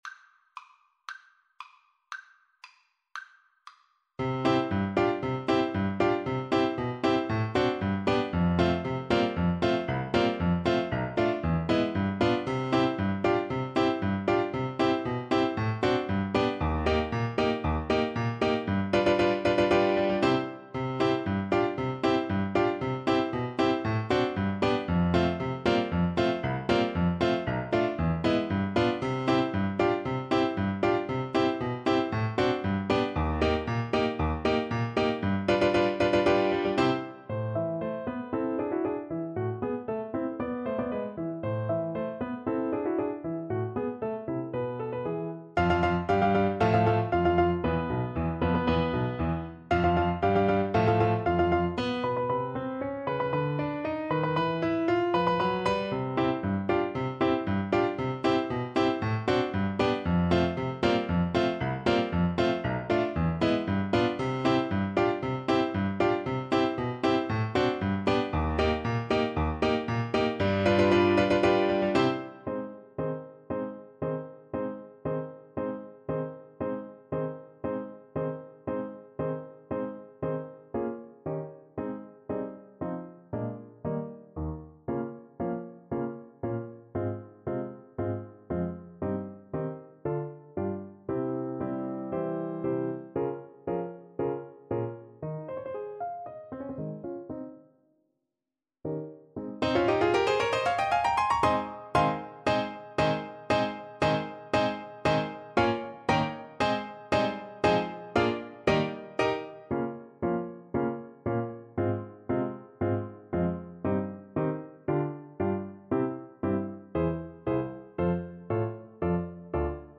Play (or use space bar on your keyboard) Pause Music Playalong - Piano Accompaniment Playalong Band Accompaniment not yet available reset tempo print settings full screen
C major (Sounding Pitch) D major (Tenor Saxophone in Bb) (View more C major Music for Tenor Saxophone )
Allegro giocoso =116 (View more music marked Allegro giocoso)
Classical (View more Classical Tenor Saxophone Music)
carmen_overture_TSAX_kar1.mp3